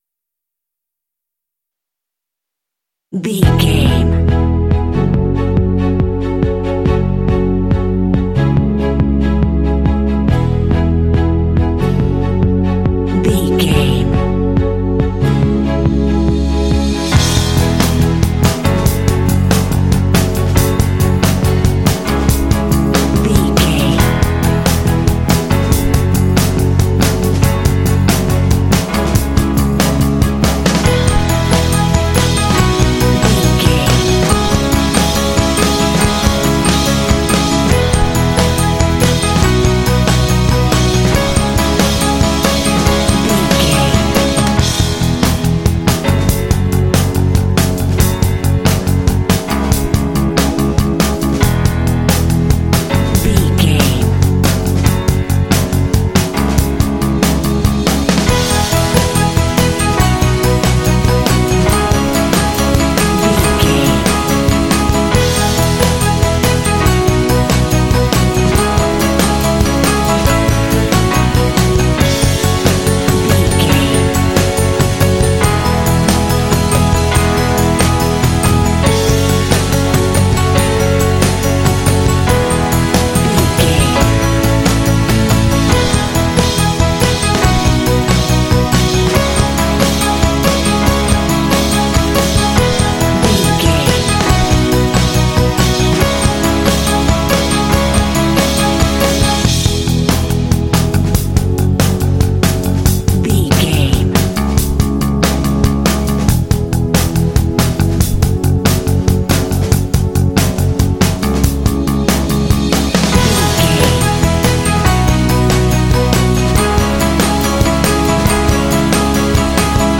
Epic / Action
Ionian/Major
hopeful
determined
strings
drums
acoustic guitar
piano
bass guitar
classic rock
alternative rock
indie